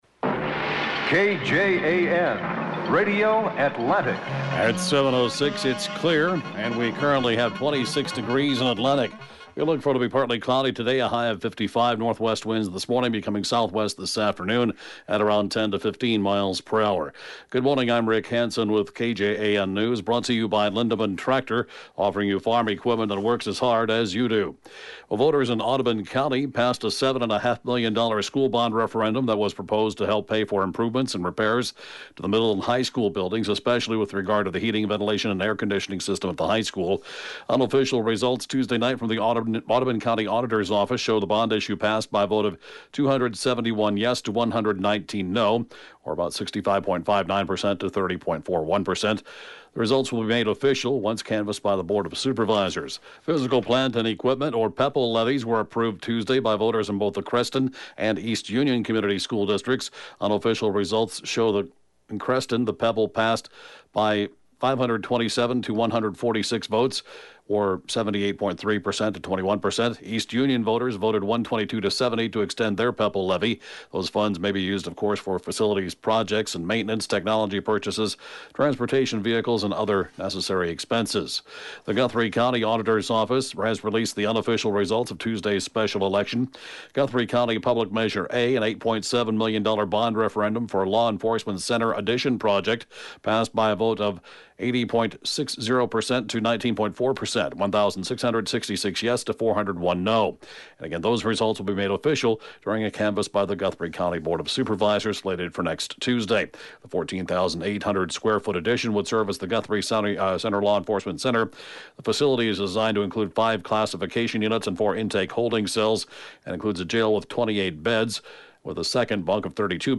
(Podcast) KJAN Morning News & Funeral report, 3/30/20